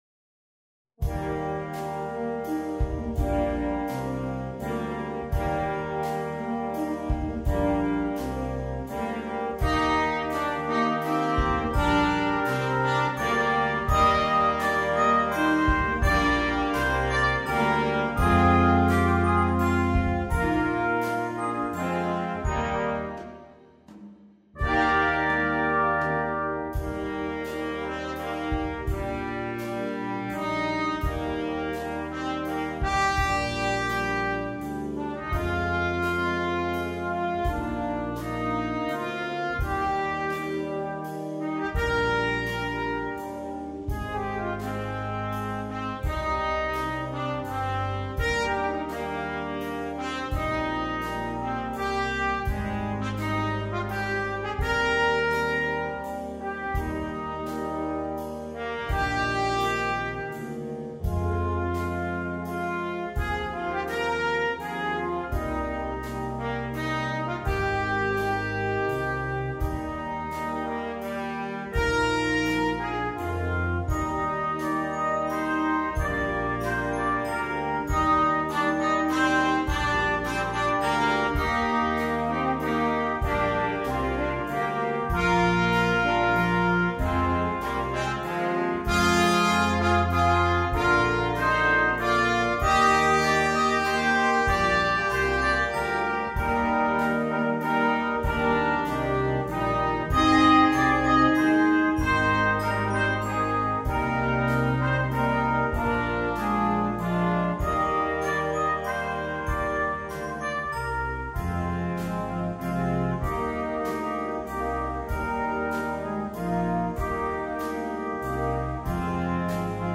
This arrangement for Brass Ensemble